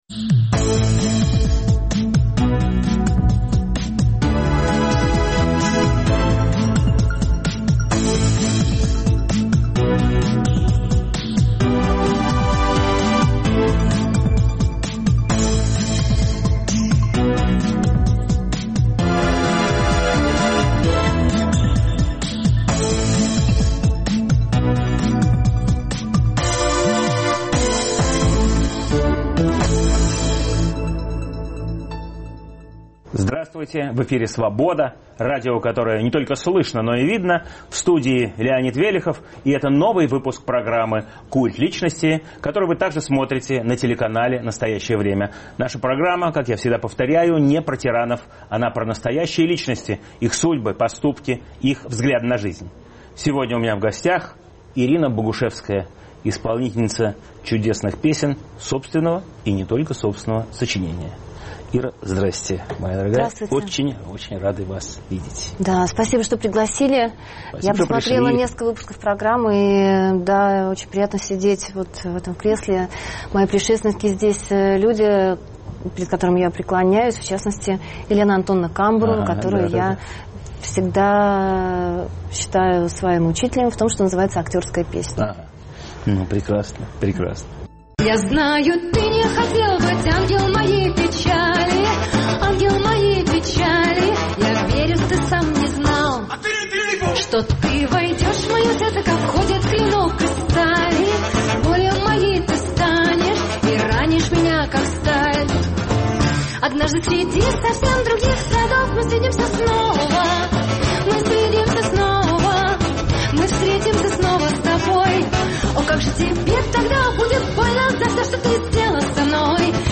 В студии "Культа личности" певица Ирина Богушевская .